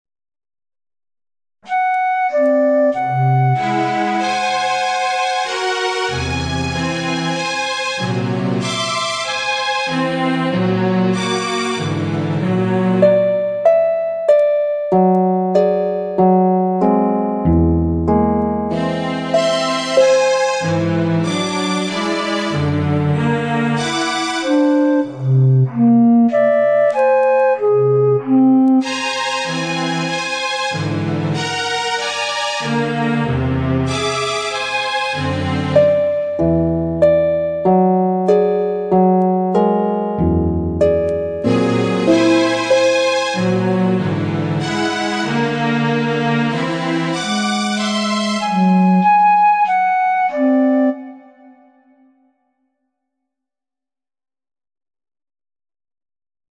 To compare the similarities between the two halves of the protein, we’ll hear them together as a duet. The two halves have been aligned so that the two sets of calcium sites play simultaneously.